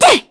Aselica-Vox_Attack3_kr.wav